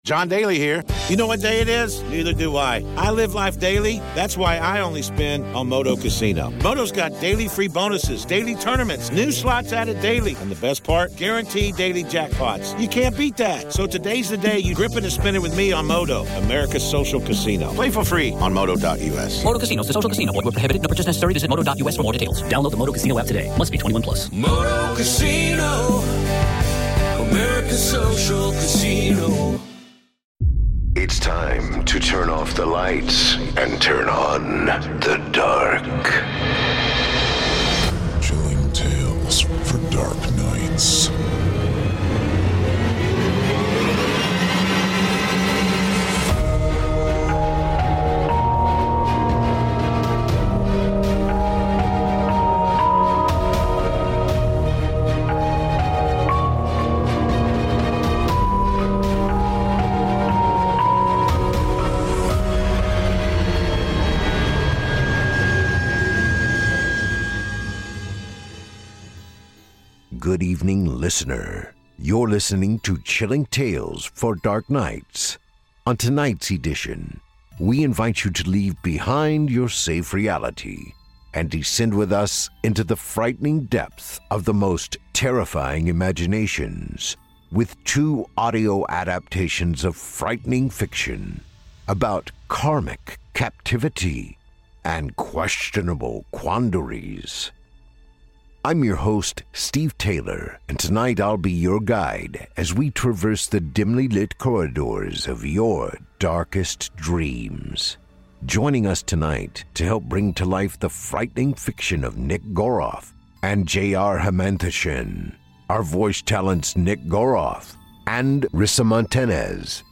On tonight’s edition, we invite you to leave behind your safe reality, and descend with us into the frightening depths of the most terrifying imaginations, with two audio adaptations of frightening fiction, about karmic captivity and questionable quandaries.